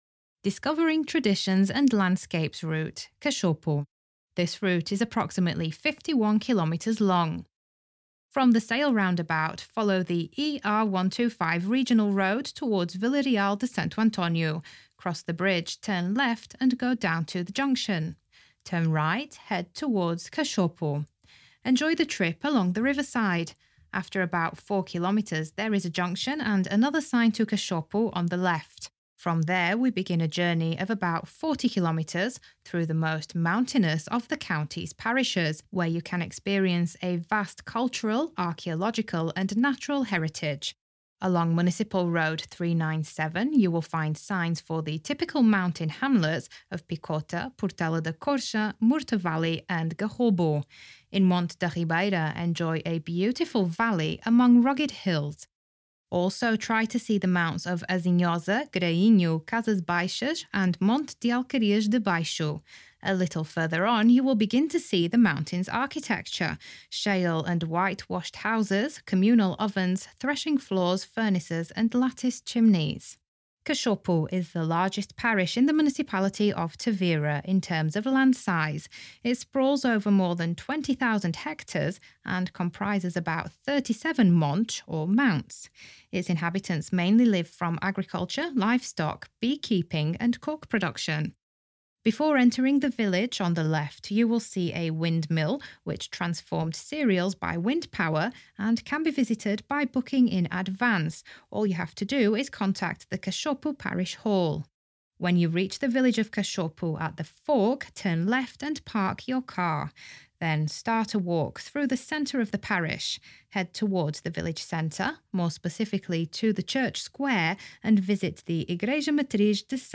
Distance 51km Average time 5 hours Main points to visit Rotunda da Vela (“Sail Roundabout”); Monte da Ribeira; Windmill; Linen Museum exhibition Audioguide_ Discovering traditions and landscapes Discovering traditions and landscapes